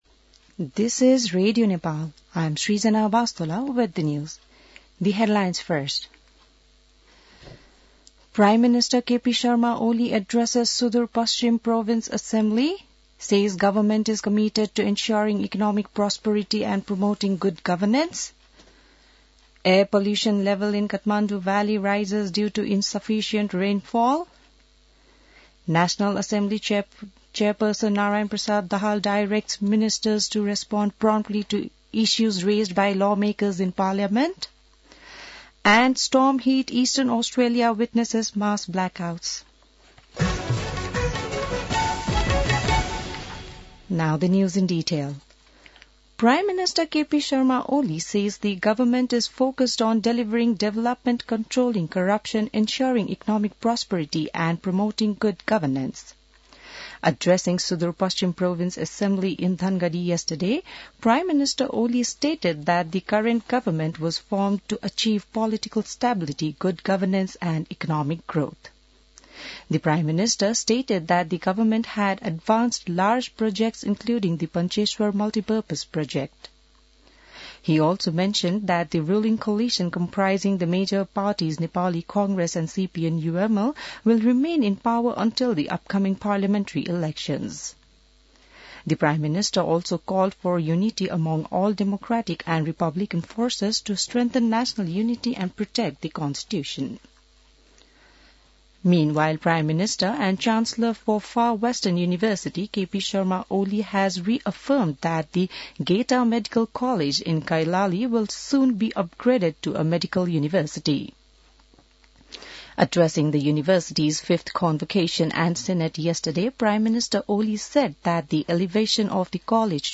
An online outlet of Nepal's national radio broadcaster
बिहान ८ बजेको अङ्ग्रेजी समाचार : २७ फागुन , २०८१